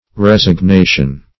Resignation \Res`ig*na"tion\ (r[e^]z`[i^]g*n[=a]"sh[u^]n), n.